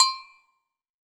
PAGOGO LW.wav